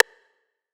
Trapaholic Rim.wav